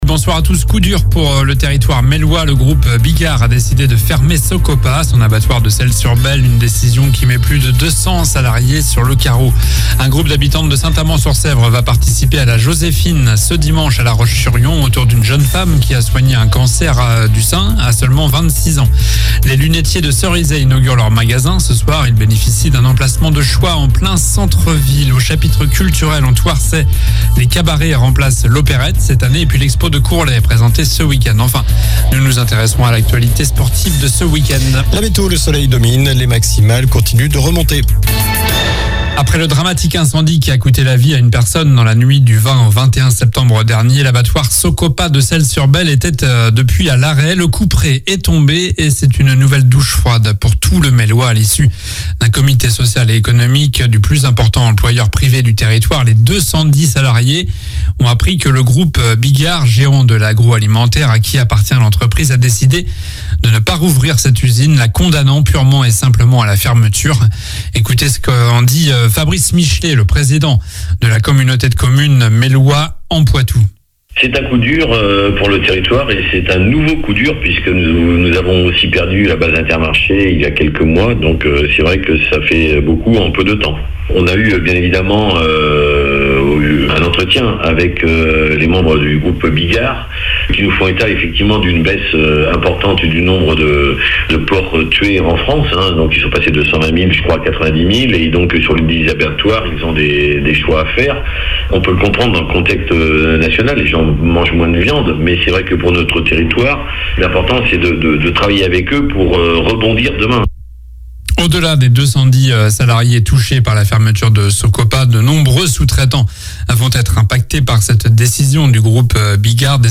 L'info près de chez vous